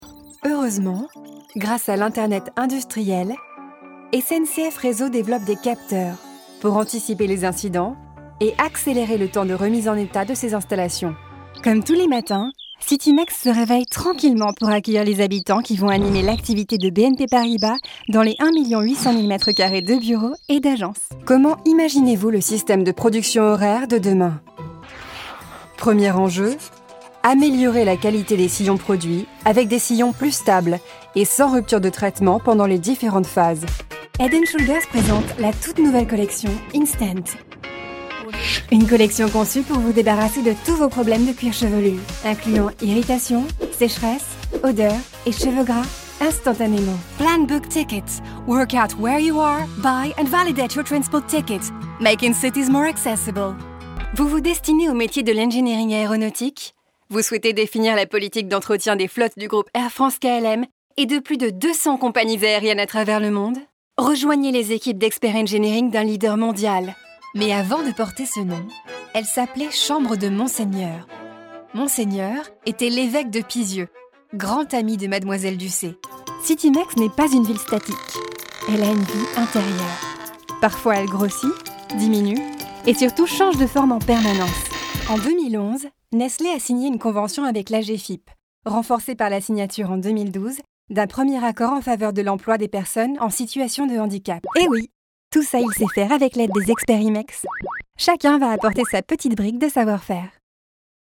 My voice is very versatile and can be lighter or deeper.
Native French voice actress and fluent English speaker.
Sprechprobe: Industrie (Muttersprache):